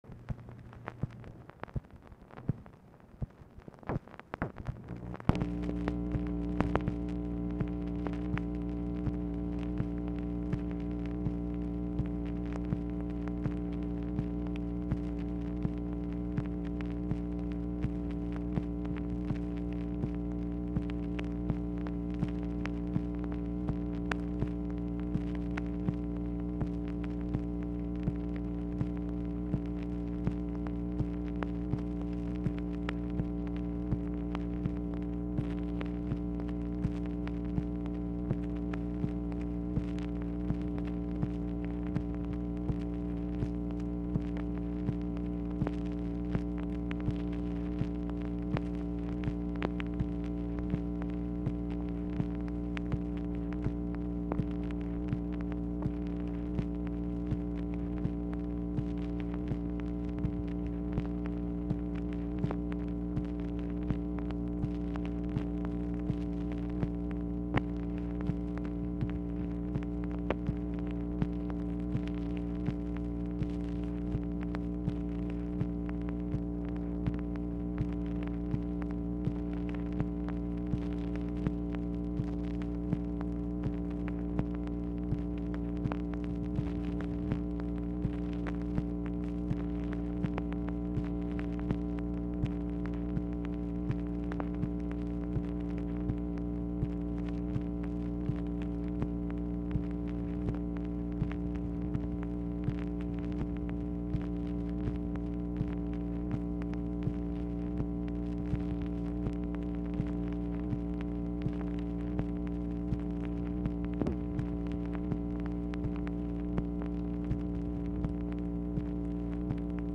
Telephone conversation # 10001, sound recording, MACHINE NOISE, 4/1/1966, time unknown | Discover LBJ
Format Dictation belt
Location Of Speaker 1 Mansion, White House, Washington, DC